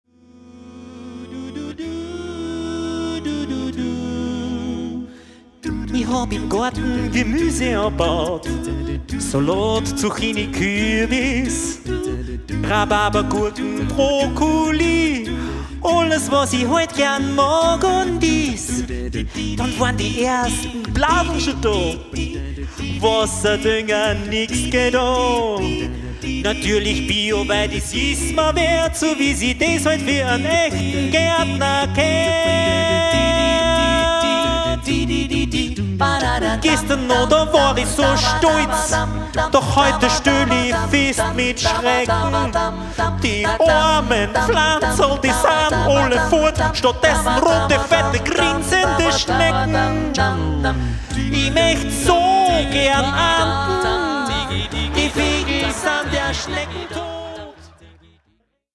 die LIVE-CD zum gleichnamigen Programm